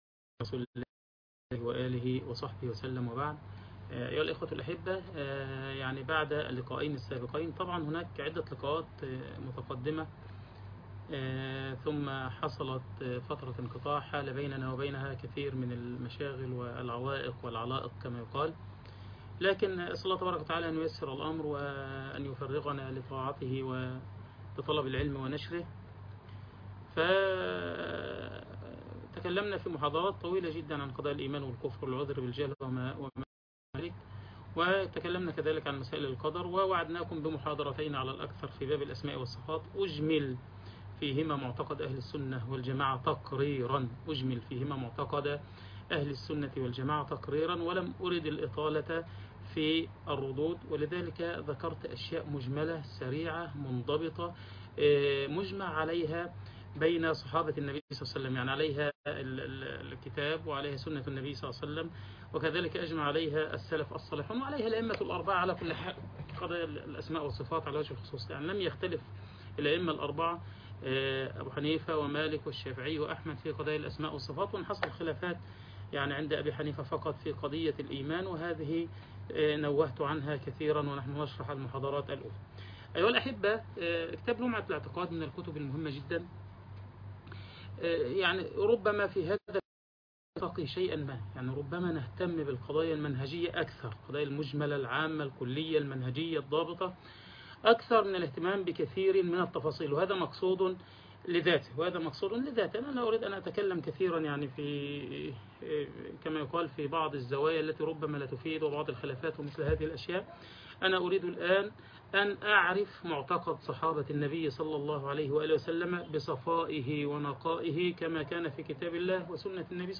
شرح لمعة الاعتقاد - الدرس الأول